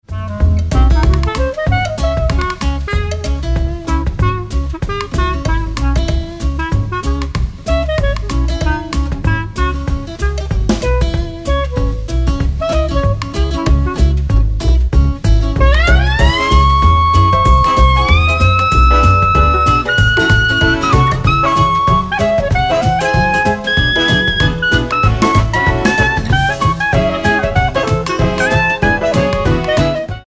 --> MP3 Demo abspielen...
Tonart:C#m ohne Chor